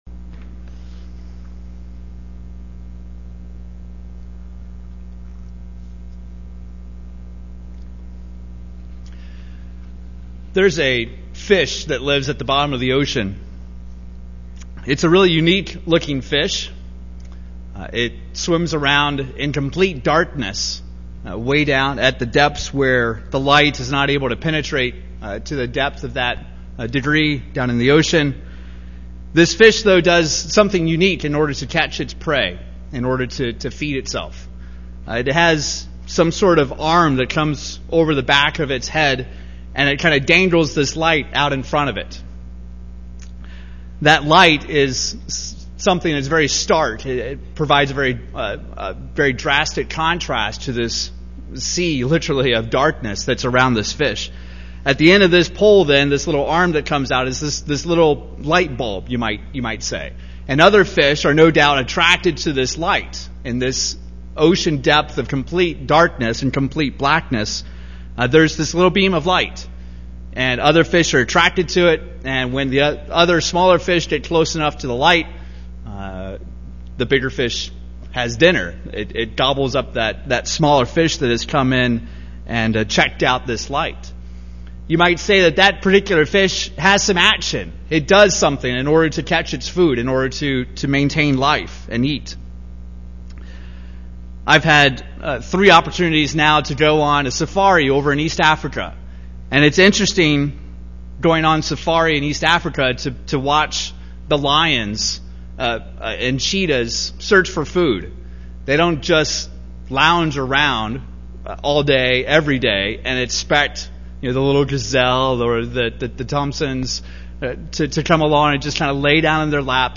These are the notes taken live during services as captioning for the deaf and hard of hearing.